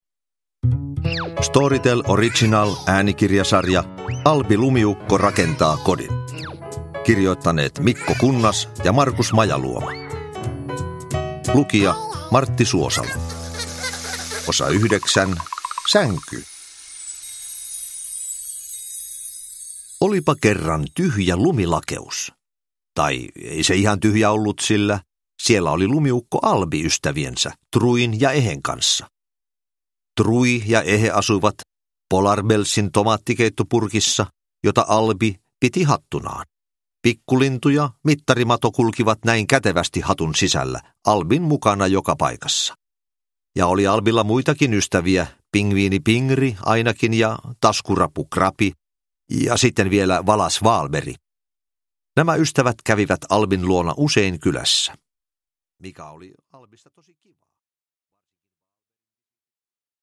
Albi rakentaa kodin: Sänky – Ljudbok – Laddas ner
Uppläsare: Martti Suosalo